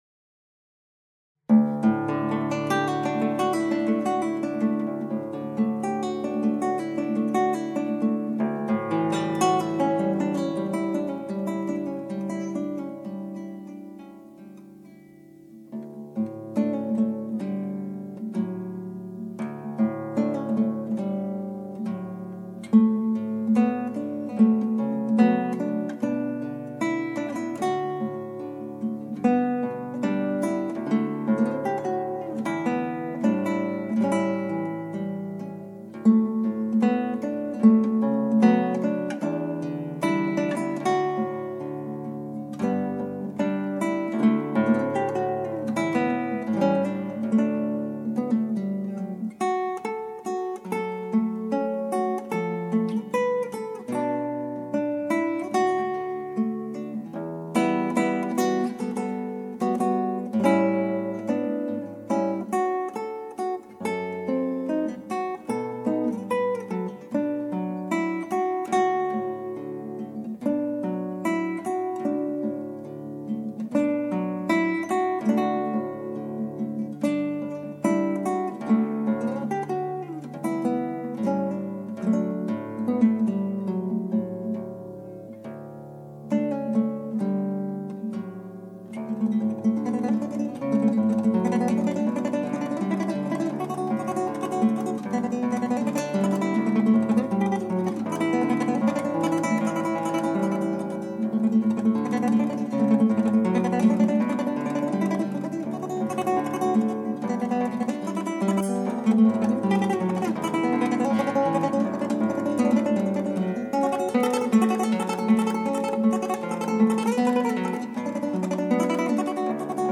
ギターの自演をストリーミングで提供
トレモロとか入れたので難しくなってしまった。